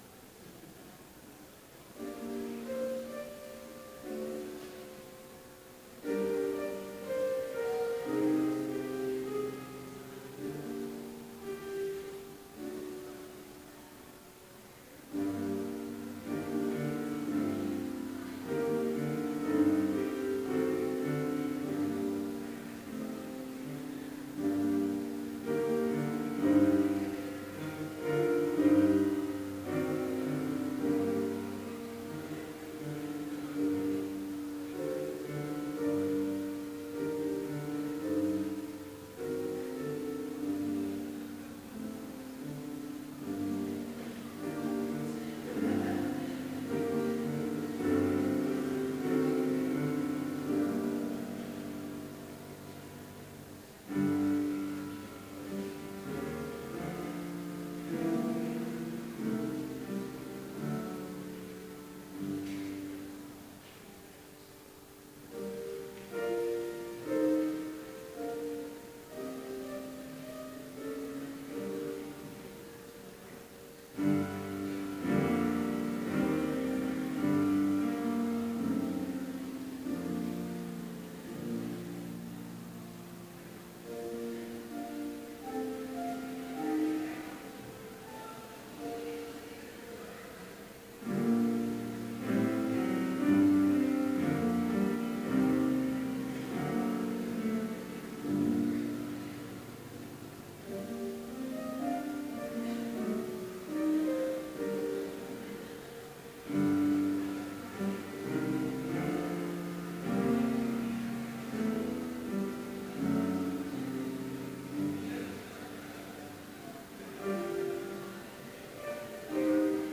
Complete service audio for Chapel - December 1, 2015